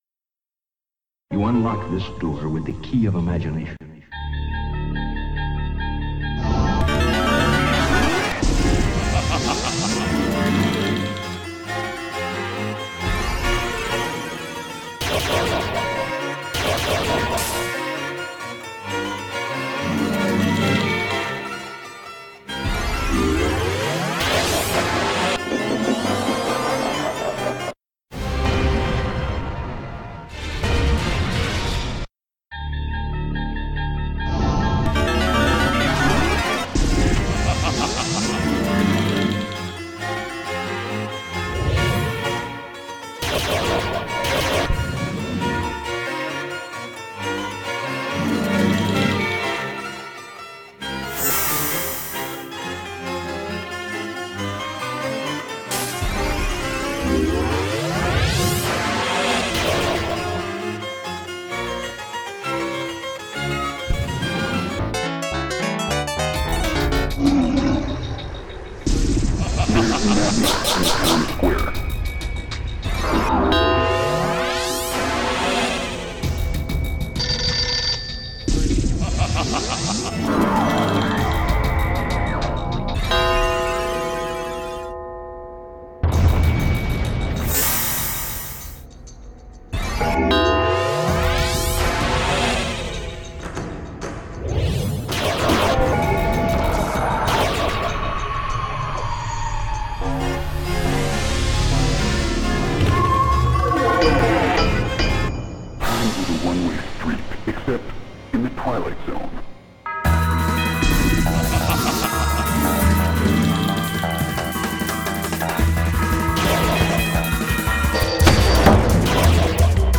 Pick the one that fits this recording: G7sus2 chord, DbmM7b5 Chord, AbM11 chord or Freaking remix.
Freaking remix